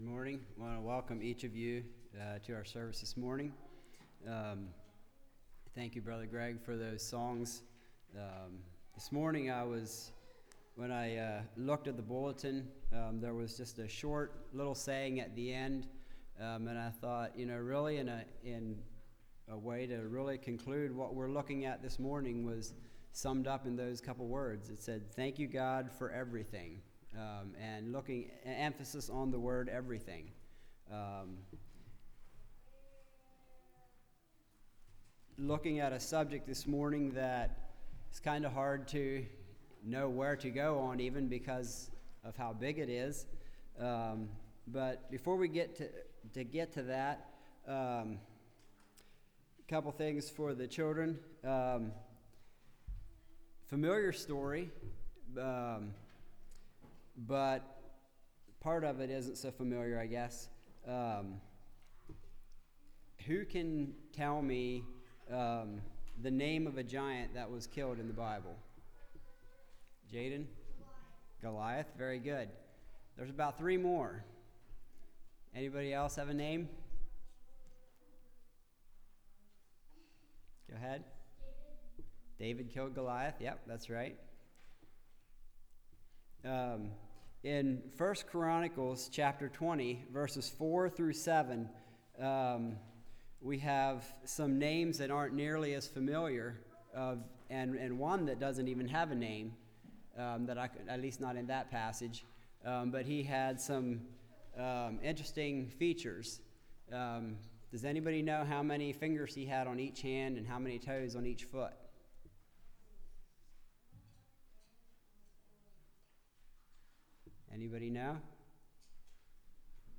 Passage: Psalms 145:1-13 Service Type: Message